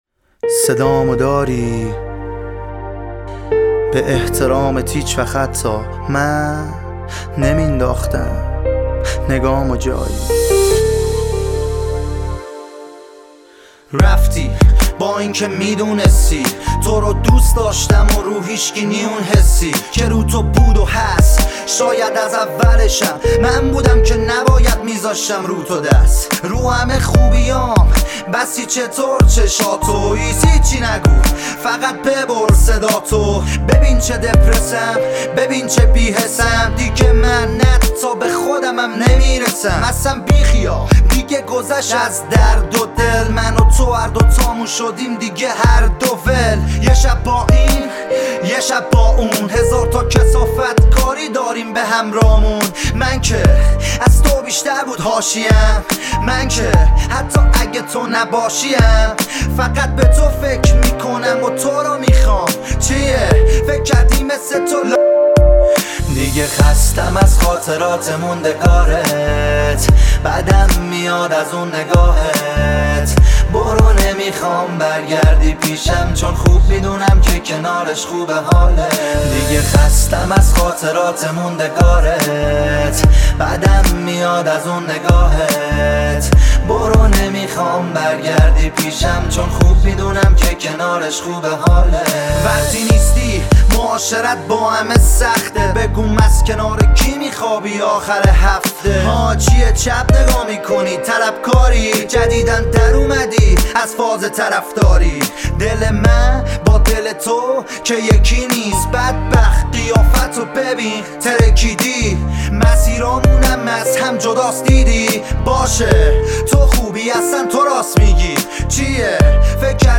ملودی دلنشین